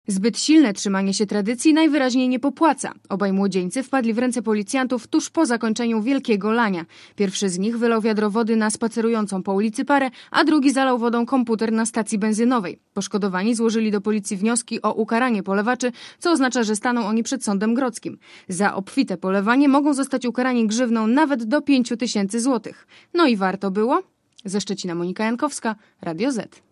Relacja reporterki Radia Zet (210Kb)